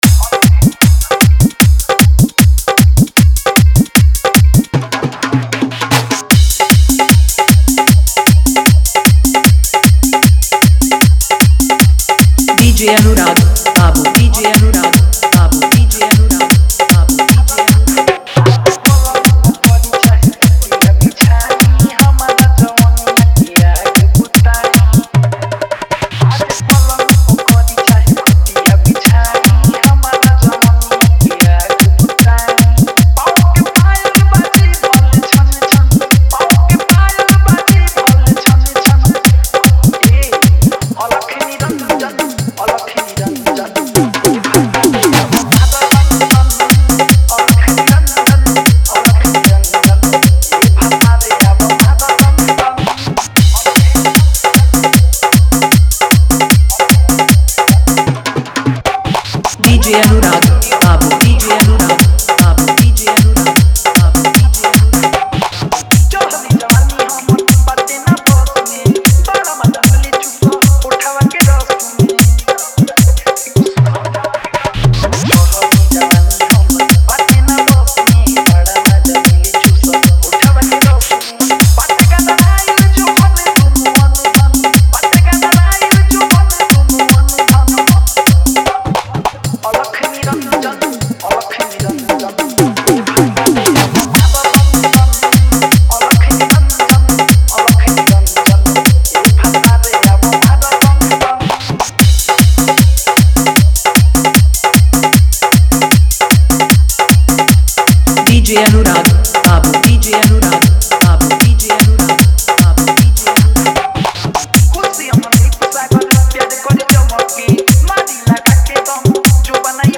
Album